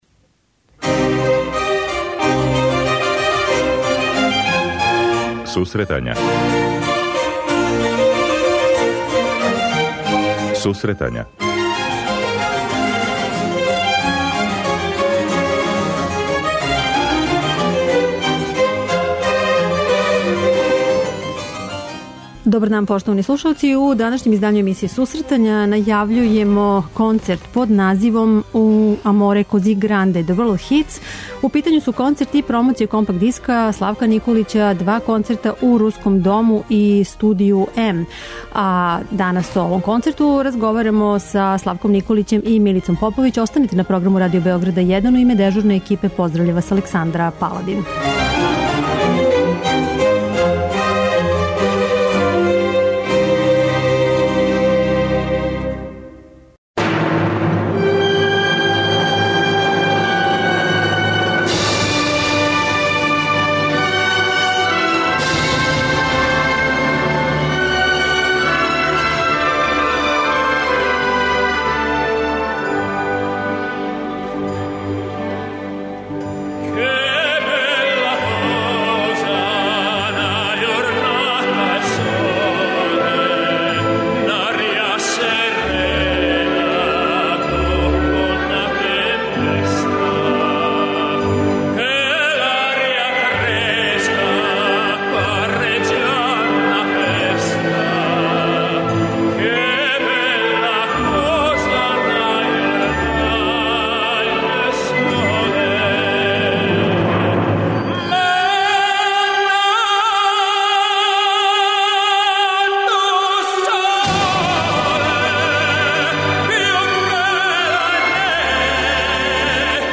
преузми : 25.93 MB Сусретања Autor: Музичка редакција Емисија за оне који воле уметничку музику.